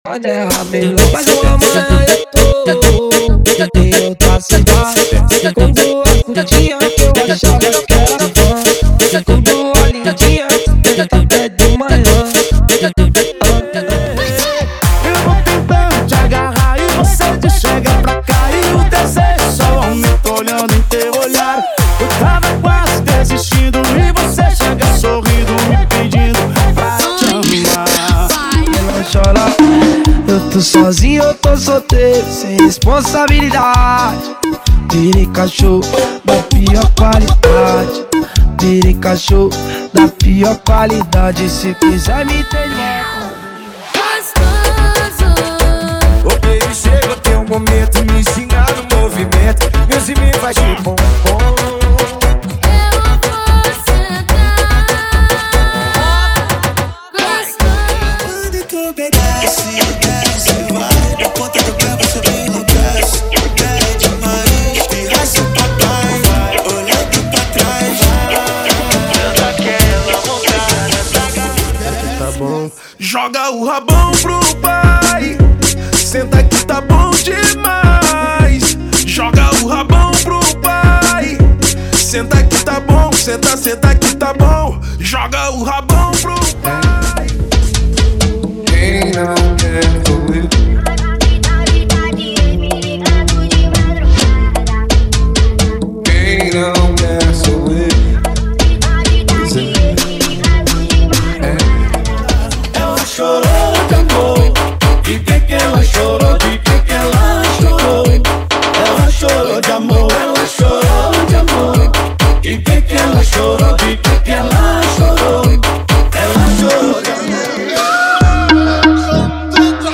• Funk Light e Funk Remix = 100 Músicas
• Sem Vinhetas
• Em Alta Qualidade